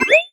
cartoon_boing_jump_03.wav